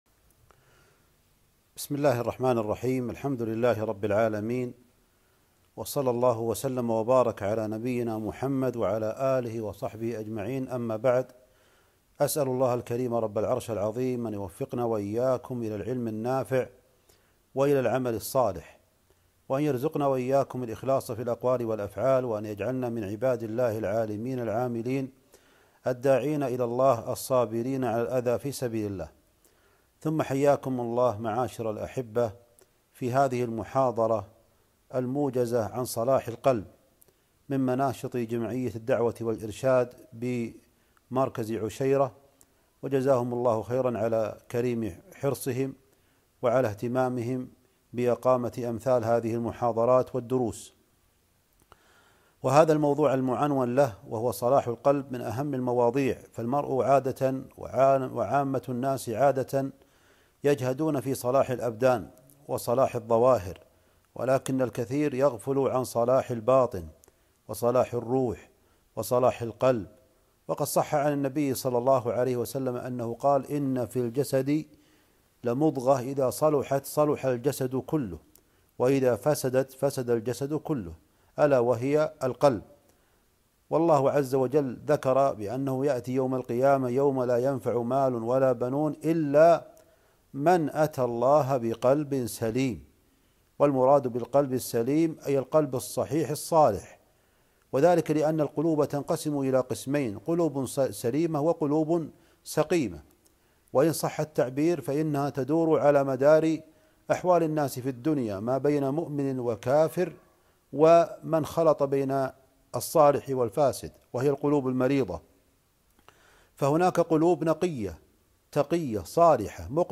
محاضرة - موجزة عن صلاح القلب